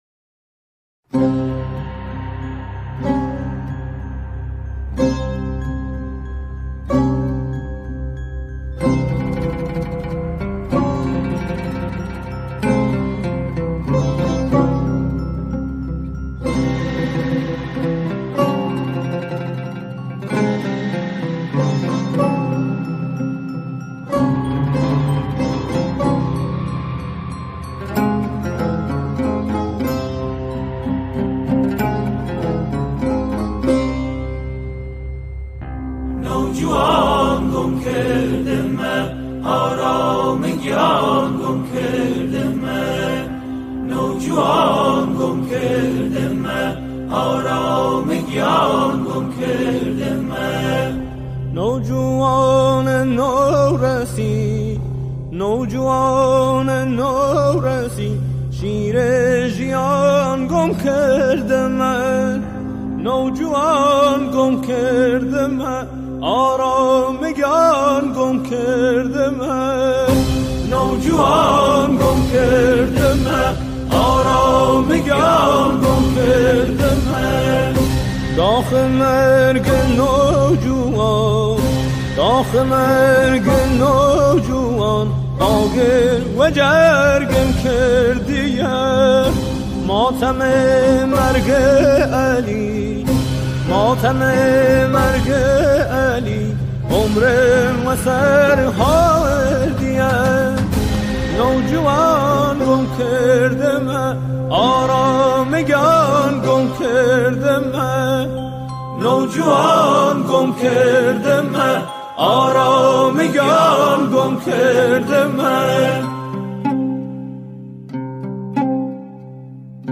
سرودهای امام حسین علیه السلام
همراهی گروه همخوان اجرا شده است